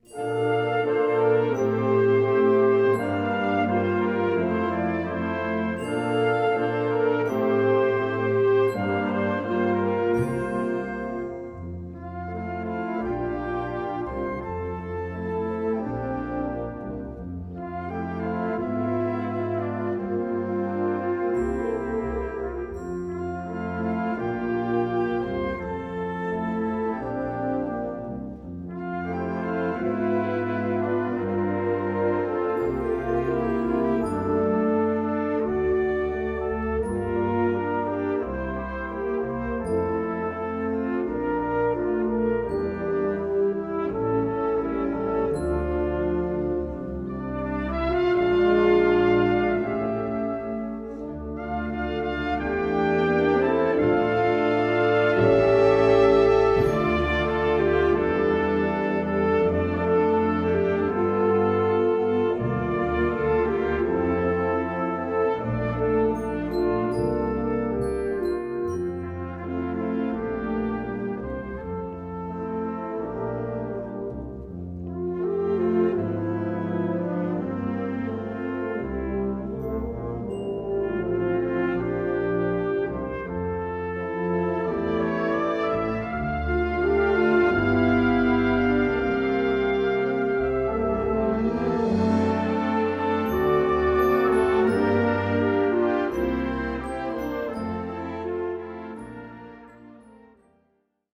Recueil pour Harmonie/fanfare - Variétés françaises